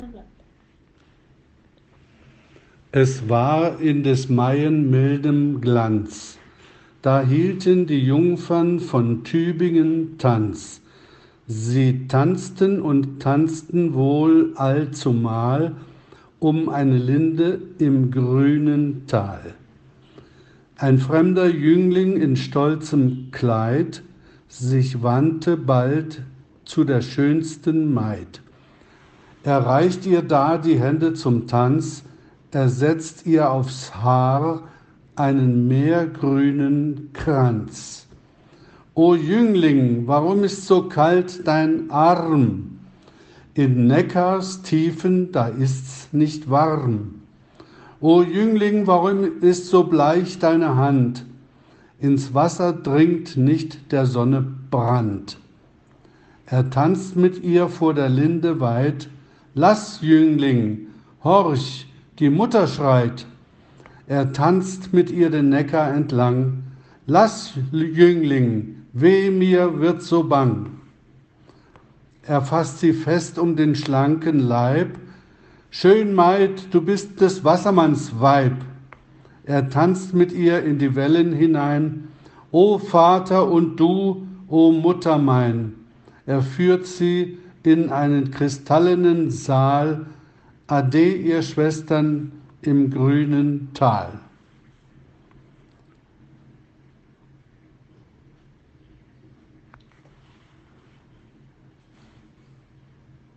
pronunciation Der Wassermann by Schumann.mp3